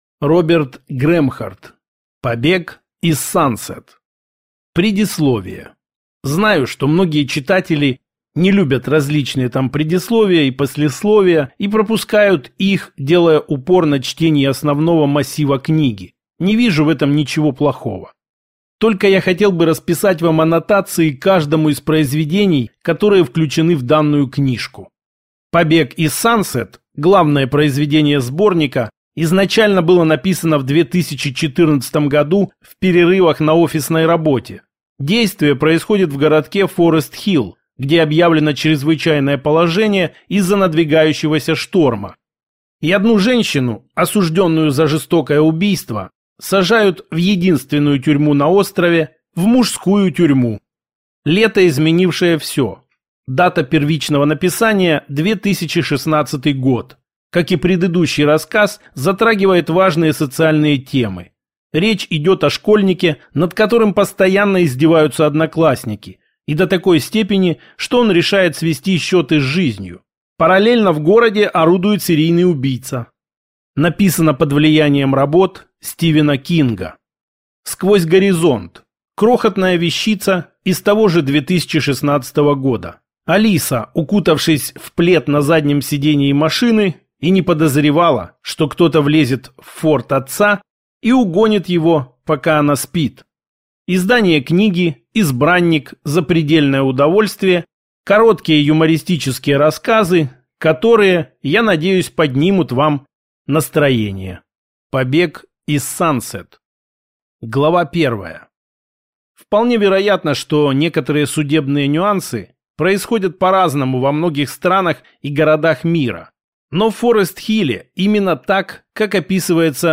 Аудиокнига Побег из Сансет | Библиотека аудиокниг